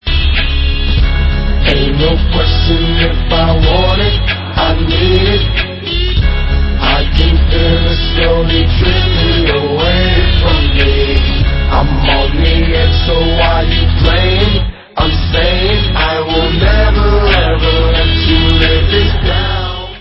sledovat novinky v oddělení Dance/Hip Hop